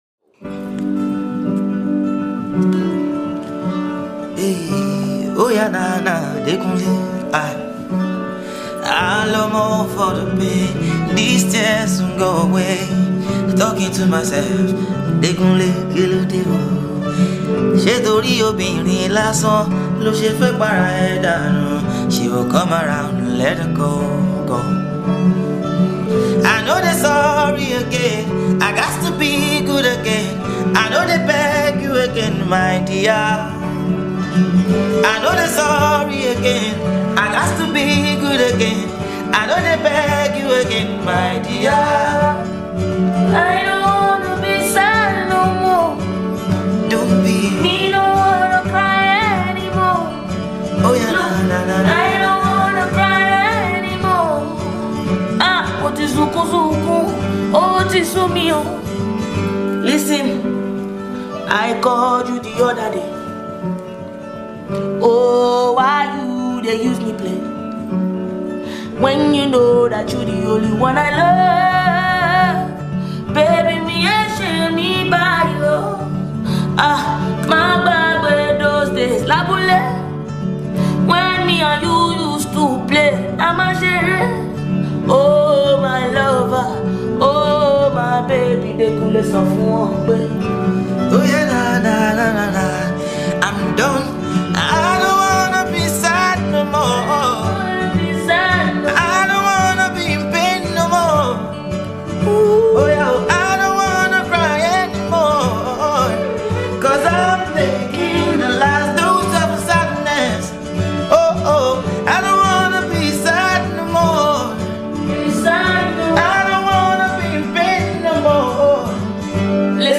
freestyle record